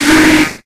Audio / SE / Cries / GLIGAR.ogg